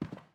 Footsteps
Carpet-10.wav